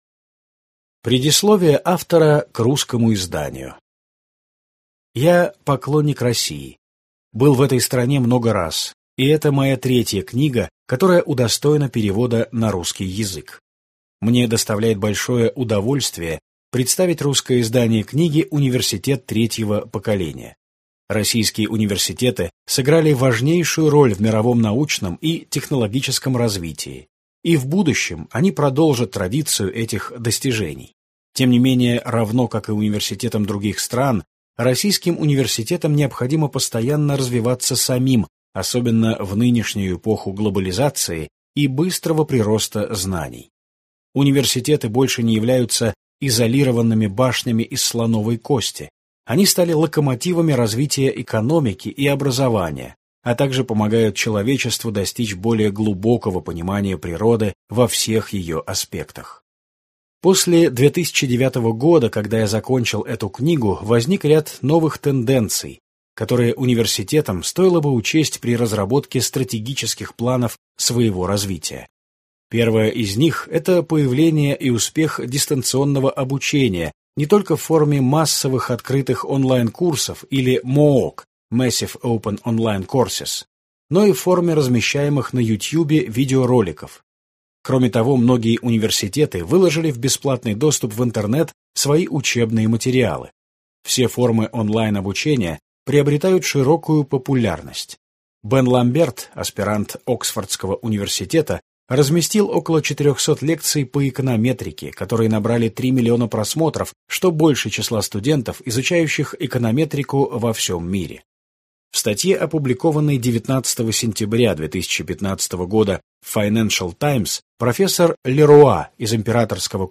Аудиокнига Университет третьего поколения | Библиотека аудиокниг